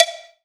1ST-COW   -R.wav